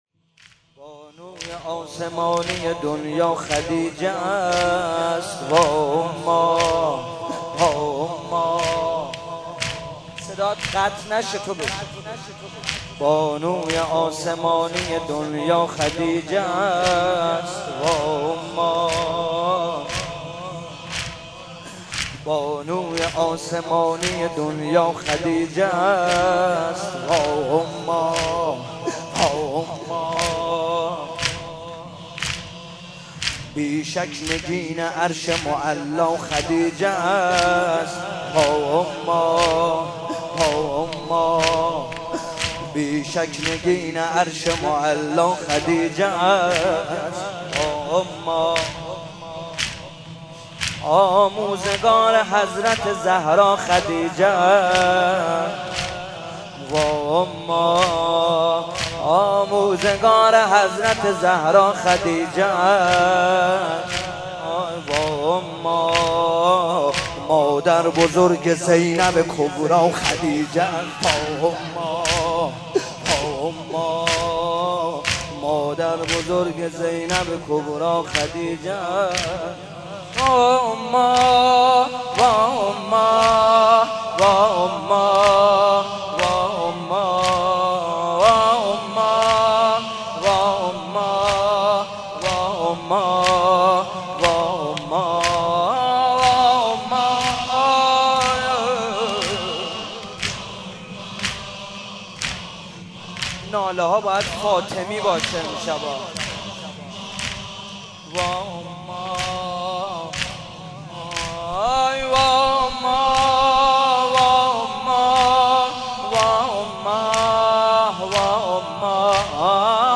شب سوم رمضان 95، حاح محمدرضا طاهری